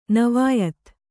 ♪ navāyat